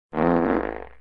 best fart
best-fart.mp3